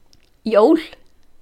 Listen to pronunciation: jól,
jol.mp3